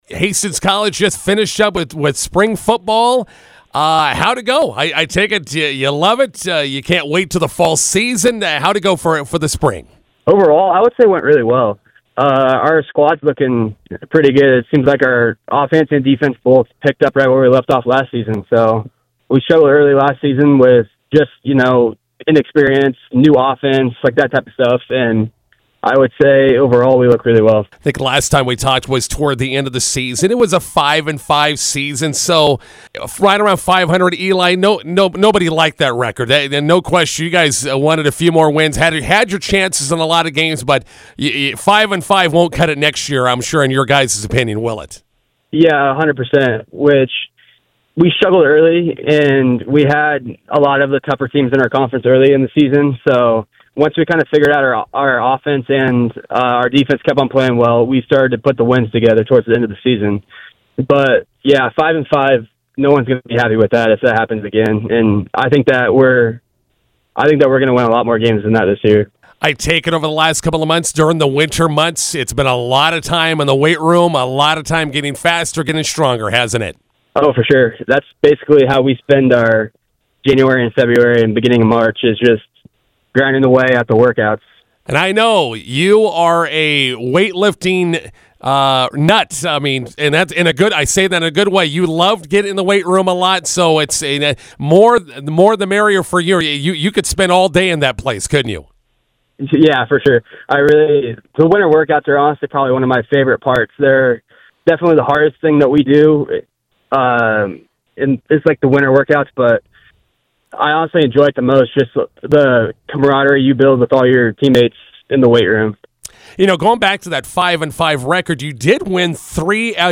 INTERVIEW: Hastings College Football wraps up spring football.